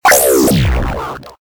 respawn.ogg